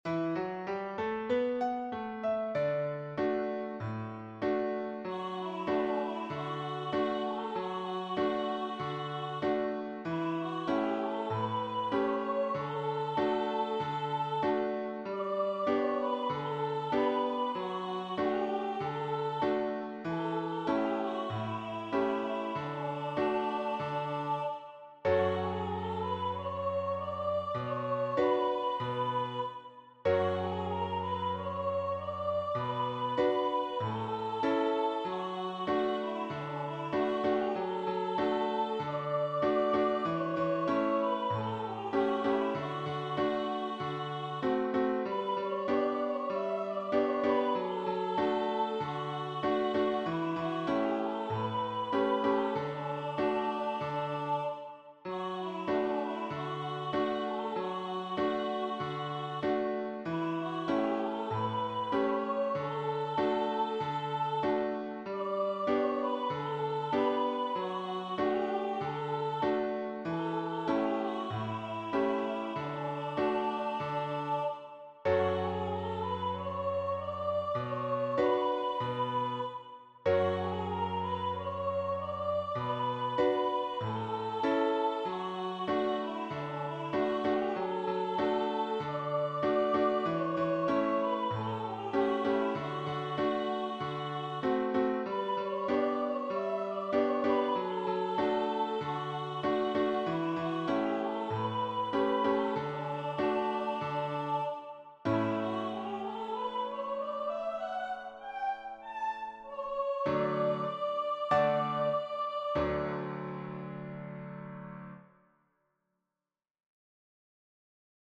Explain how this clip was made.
rehearsal file